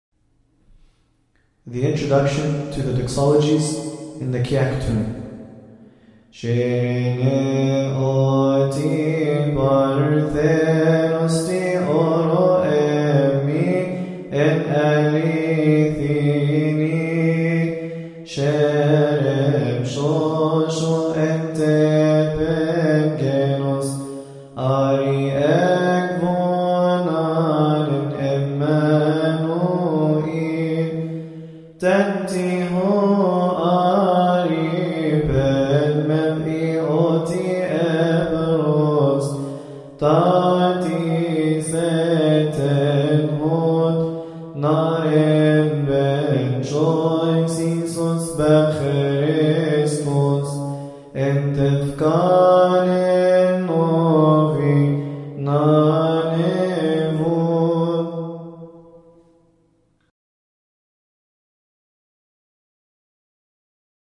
All hymns must be chanted according to the Higher Institute of Coptic Studies.
Kiahky, Annual, Joyful,